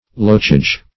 lochage - definition of lochage - synonyms, pronunciation, spelling from Free Dictionary
Search Result for " lochage" : The Collaborative International Dictionary of English v.0.48: Lochage \Loch"age\, n. [Gr.?.]